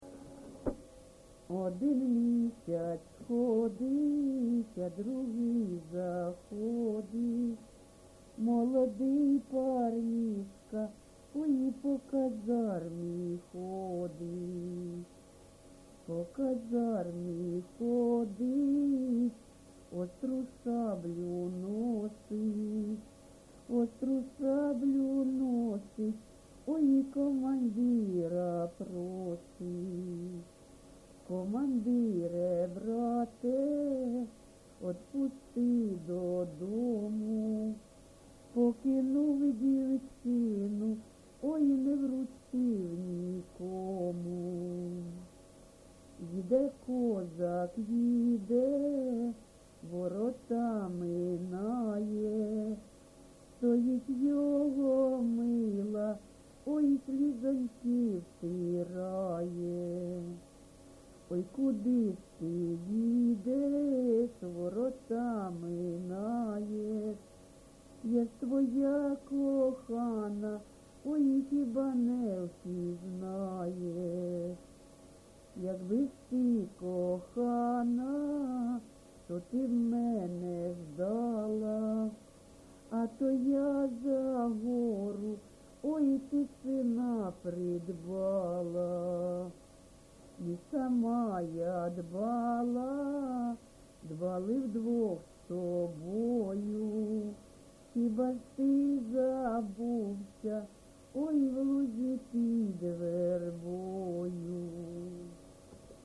ЖанрПісні з особистого та родинного життя
Місце записум. Бахмут, Бахмутський район, Донецька обл., Україна, Слобожанщина